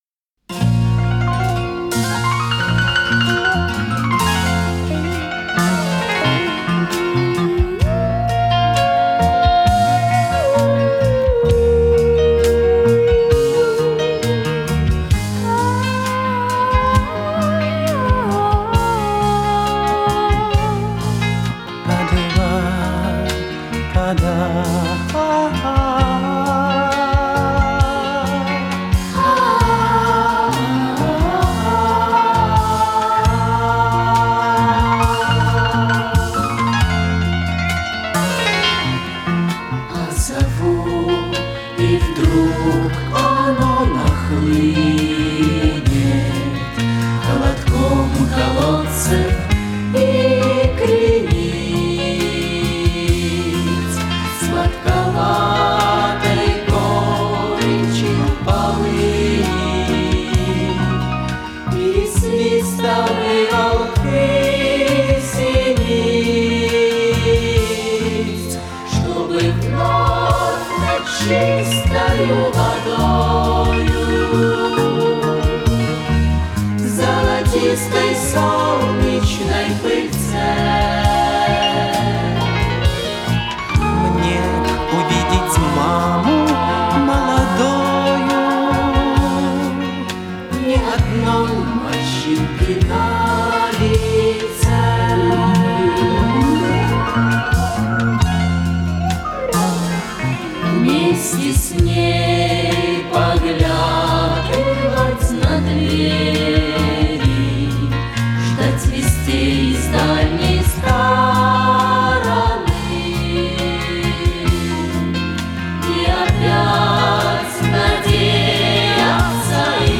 тут качество чуток повыше будет